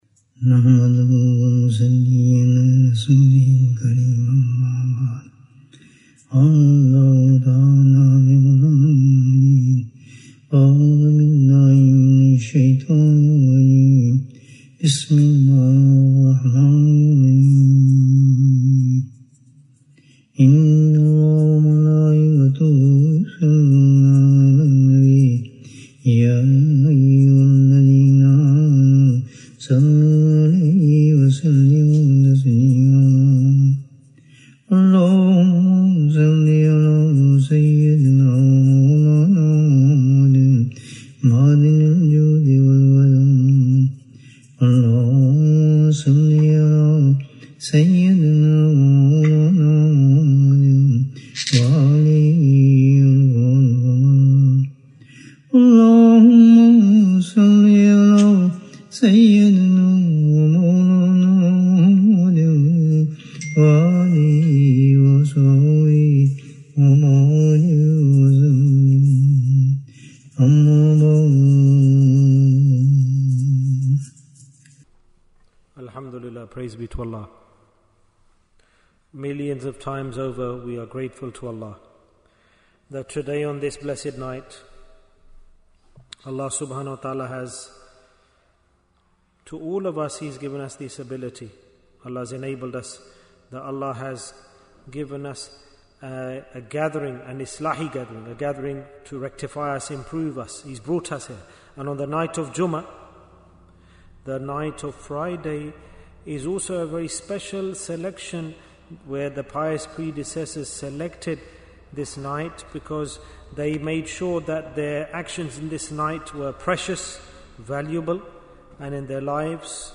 The Way to Jannah Bayan, 26 minutes12th June, 2025